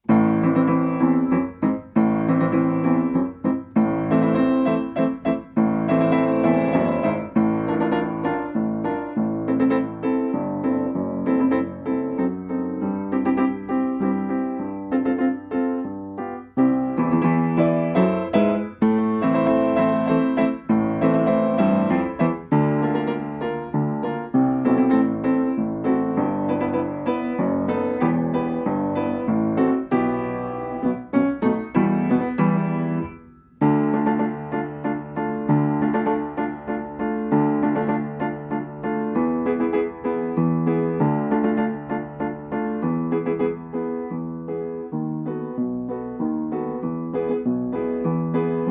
Strój 440Hz
I wersja – wirtuozowska
Tempo: 100 bmp
Nagrane bez metronomu.
Nagranie uwzględnia zwolnienia.
piano